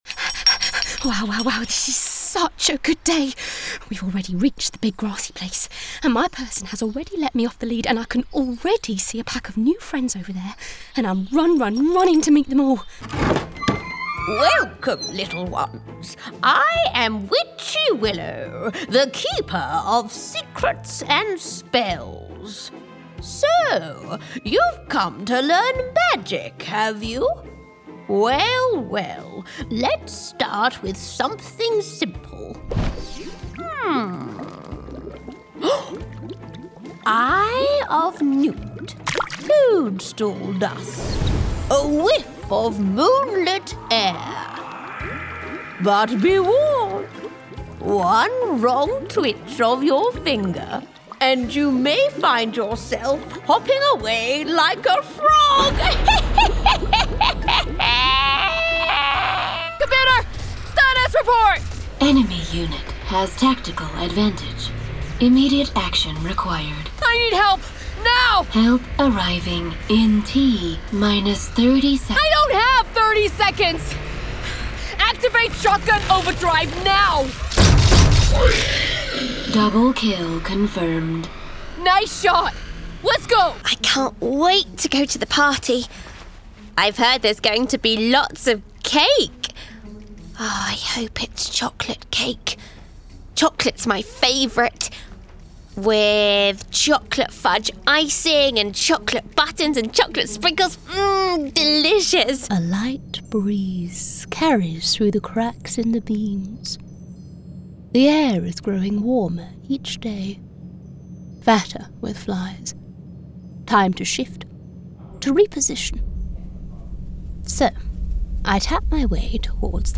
Character Showreel
She is adaptable, versatile and a great storyteller, with many regional accents under her belt as well!
Female
London
Neutral British
Bright
Down To Earth
Friendly
Reassuring